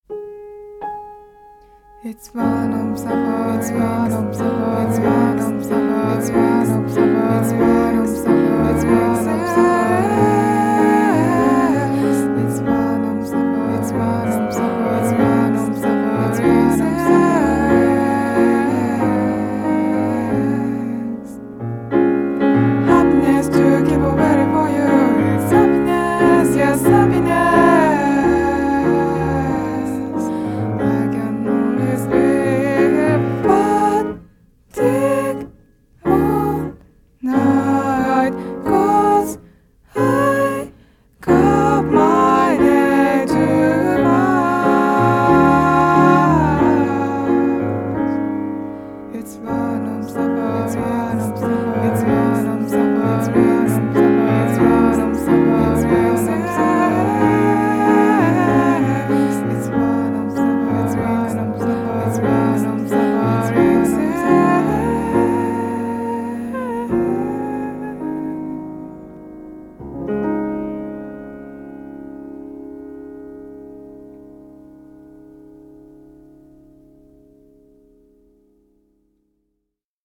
シンプルかつ端正なピアノと凛々しさと浮遊感が同居した歌が、淡く切ない詩情を呼び込む傑作！